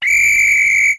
blows his whistle to get the team out of the pub.
aud_whistle_short.mp3